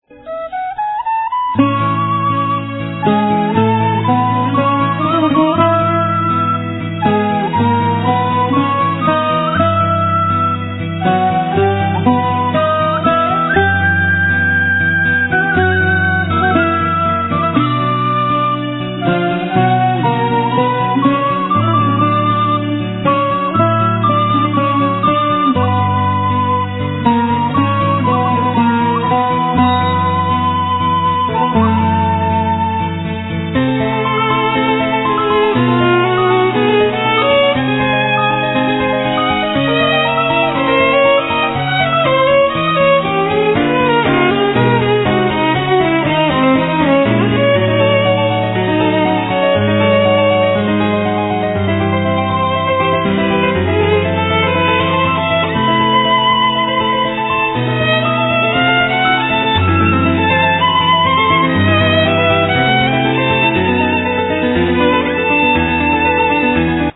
Sevi, Doudouk
Violin, Viola
Acoustic bass
Drams, Percussions
Bouzouki
Piano, Orchestration, Programming